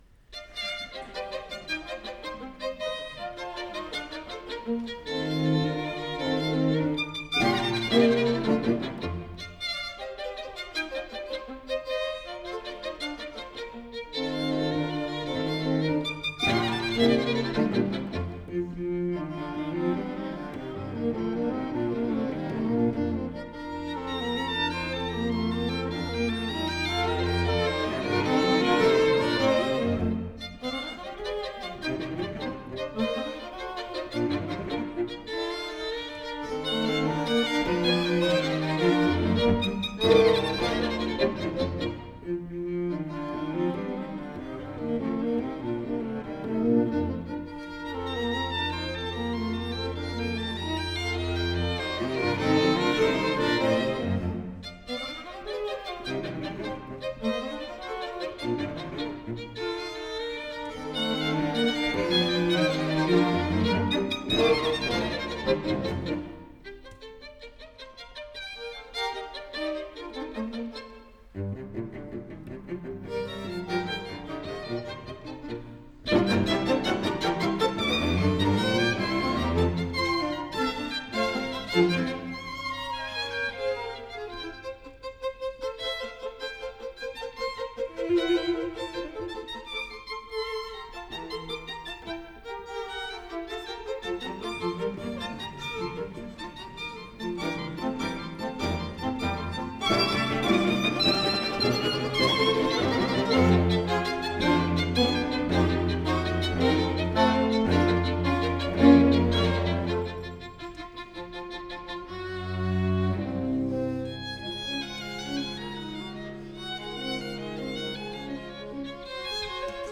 4ème mouvement (Allegro assai